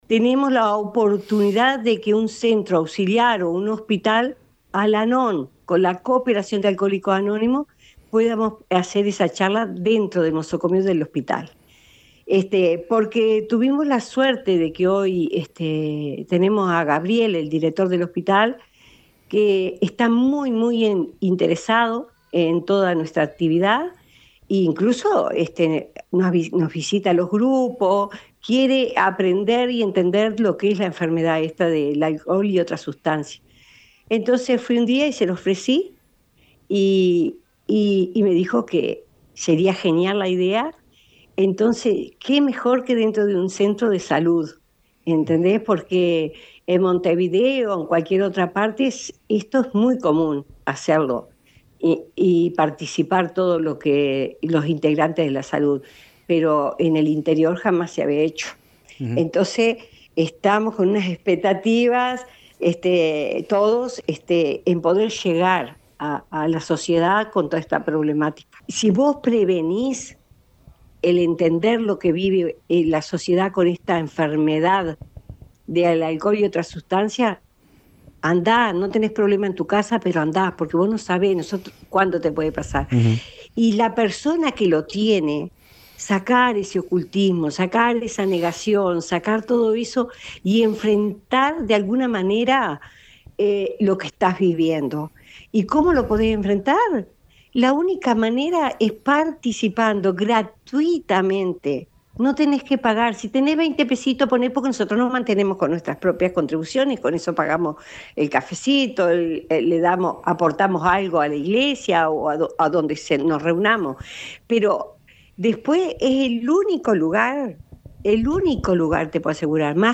Escuchamos a una de las integrantes del grupo Al-Anon de Nueva Helvecia quien llamó a la población a concurrir a la misma porque es un problema que nos afecta a todos y a todas las familias, destacó…